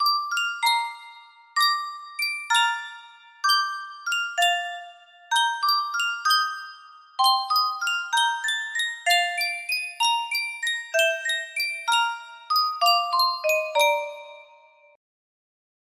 Yunsheng Boite a Musique - Erik Satie Je Te Veux 6263 music box melody
Full range 60